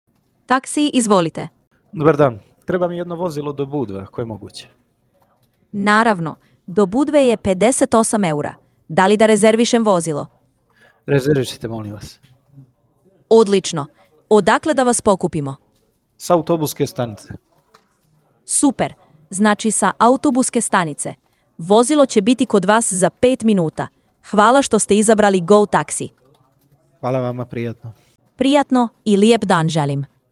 Linia je sistem zasnovan na vještačkoj inteligenciji koji automatski prima i upućuje telefonske pozive, razgovara sa korisnicima i pruža tačne informacije – prirodnim, ljudskim glasom.
Preslušajte primjere AI glasovnog agenta